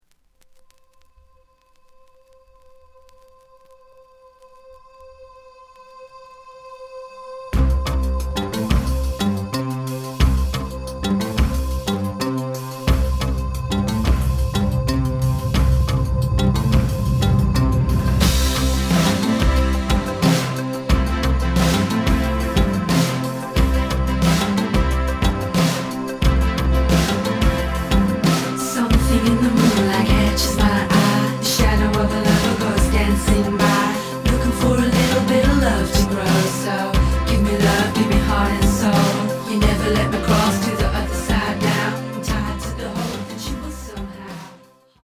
The audio sample is recorded from the actual item.
●Genre: Rock / Pop
Slight edge warp. But doesn't affect playing. Plays good.